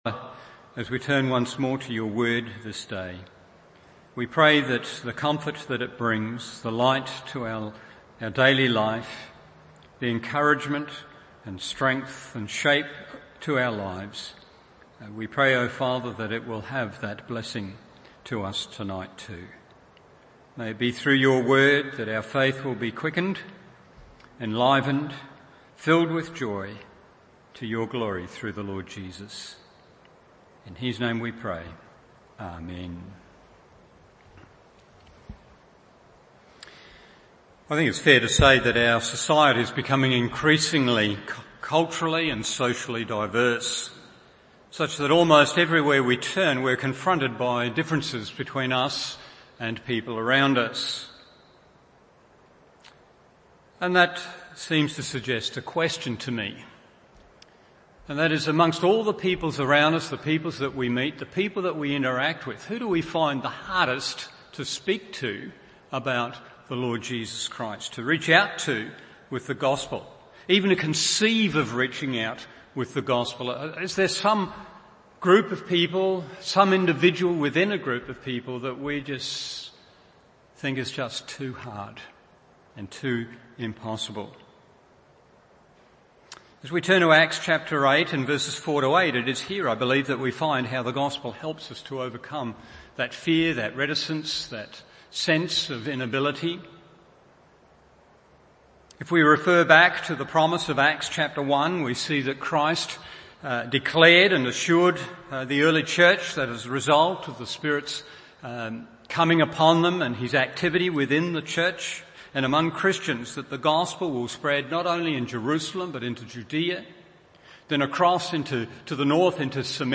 Evening Service Acts 8:4-8 1. The Exposition of Gospel Truth 2. The Exposition of Gospel Power 3. The Exposition of Gospel Joy…